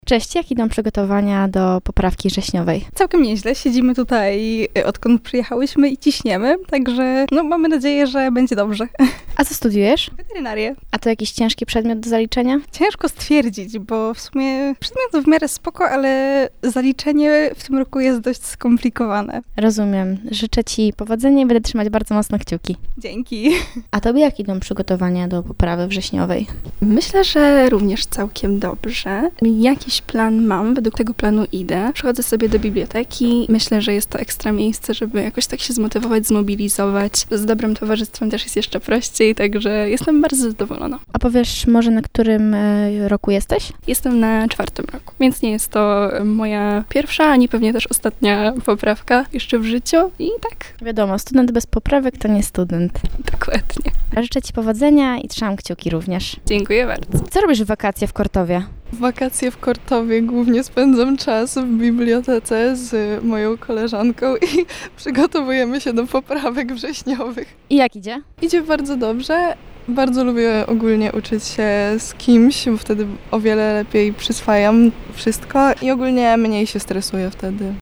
Studenci już w tym tygodniu przygotowywali się do egzaminów poprawkowych w Kortowie.